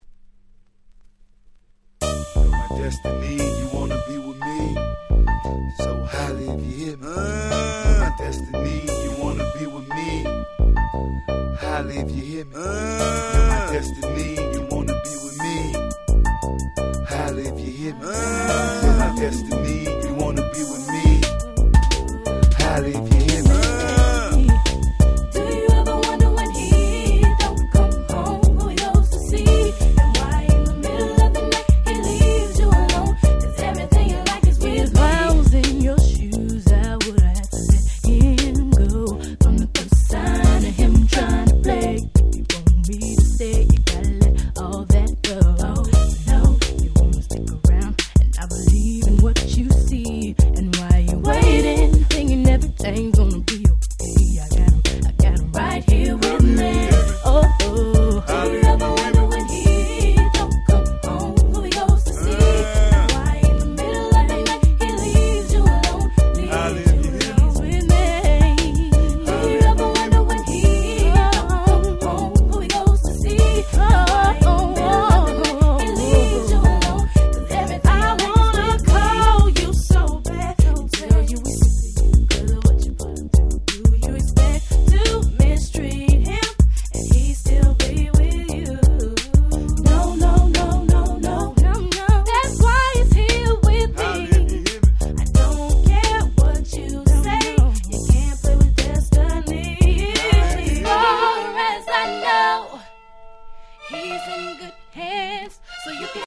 98' Smash Hit R&B !!